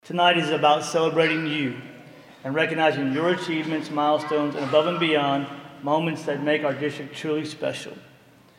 Wednesday night, some employees, their families, and school officials gathered to celebrate several dozen coworkers.
click to download audioClassified and Certified Staff of the Year from each school were also recognized, with Bentzel saying the 32 honorees are all well deserving.